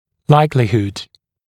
[‘laɪklɪhud][‘лайклихуд]вероятность